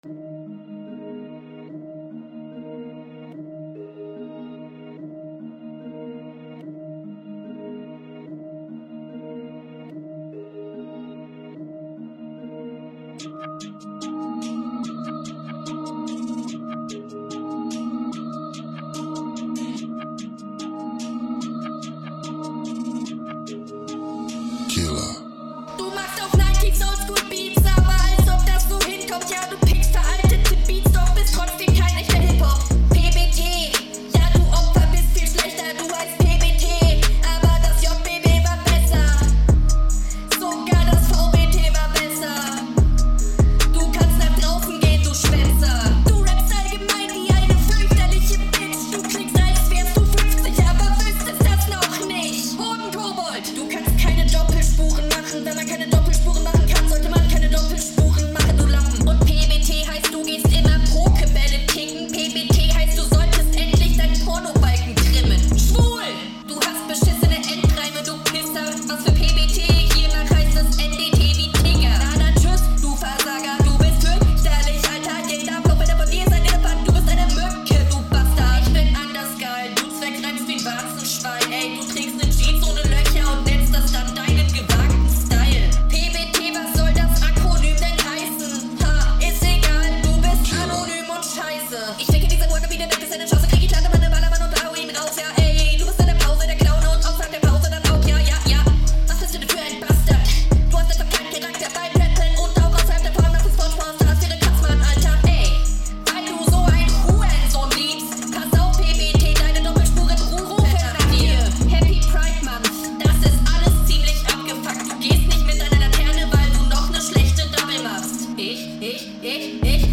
Der Sound um das Mikrofon falsch herum zu verwenden